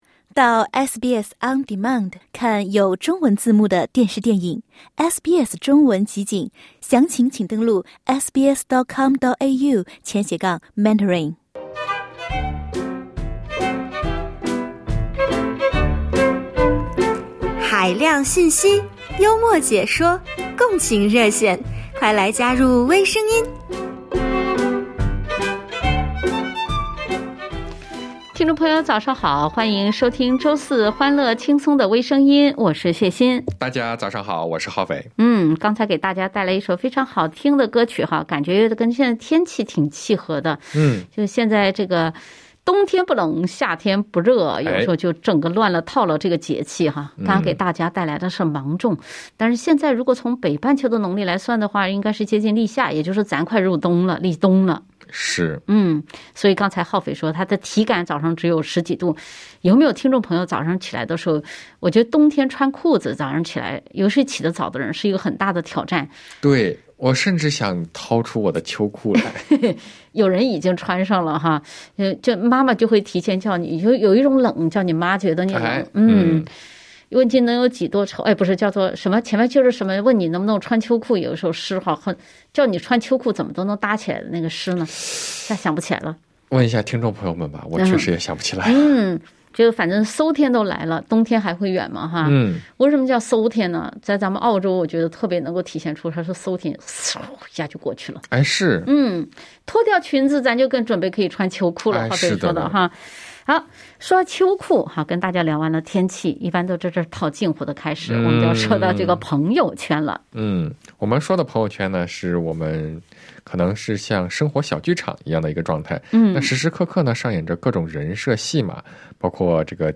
热心听众分享自己对“朋友圈”的态度。